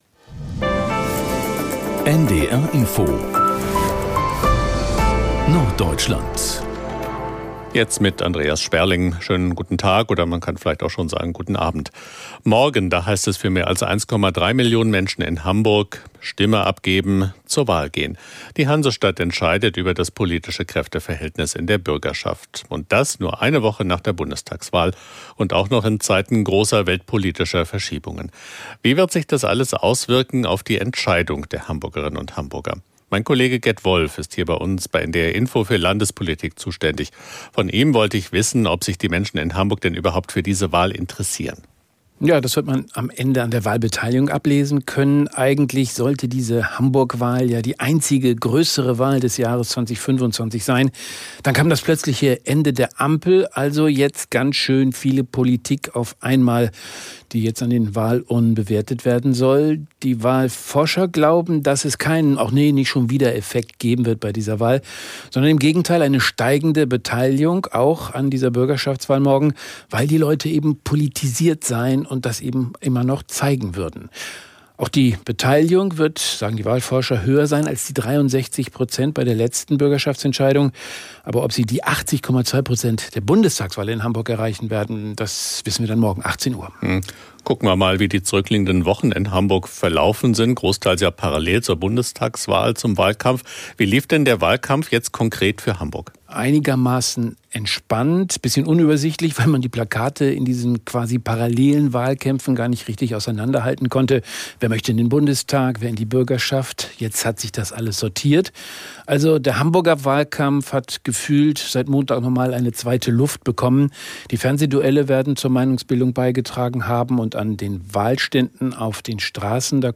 … continue reading 533 قسمت # Nachrichten # NDR Info # Tägliche Nachrichten